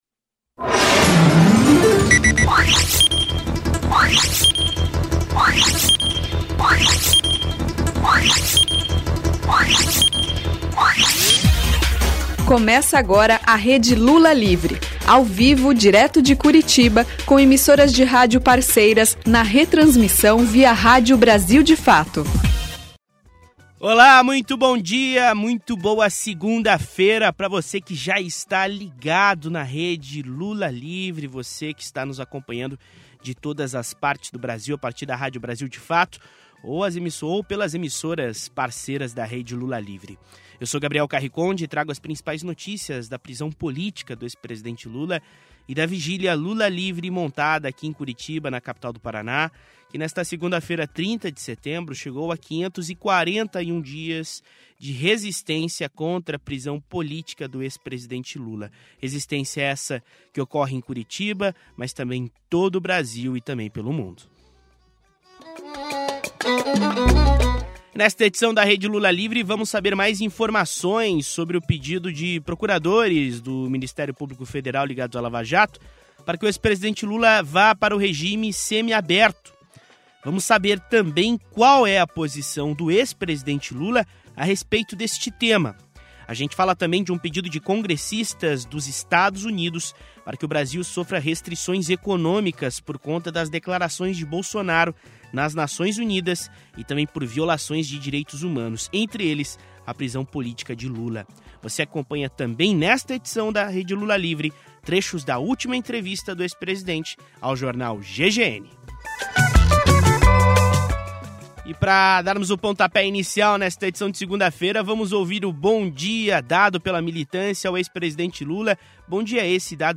A Rede Lula Livre vai ao ar de segunda a sexta-feira, das 9h45 às 10h (horário de Brasília), na Rádio Brasil de […]